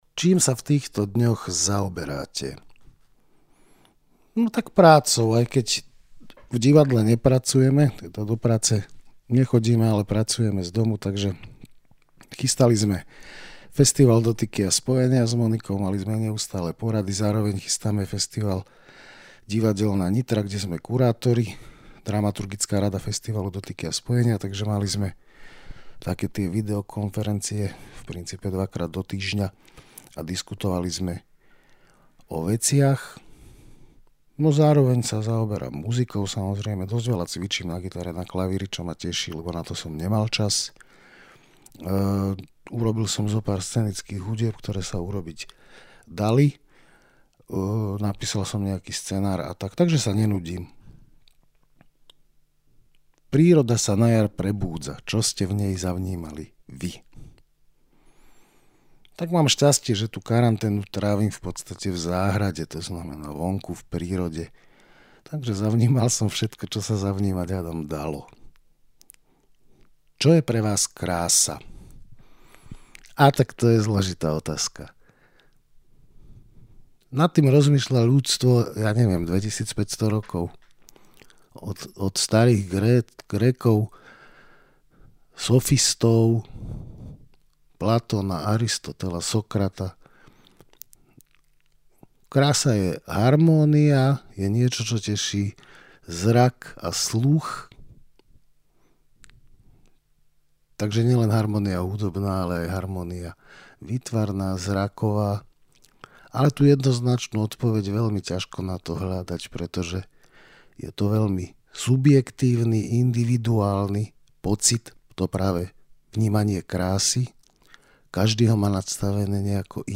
Milí naši priaznivci, pripravili sme pre vás sériu krátkych rozhovorov so zaujímavými, známymi ľuďmi.